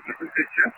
I recorded this EVP while taking pictures during a session in my room.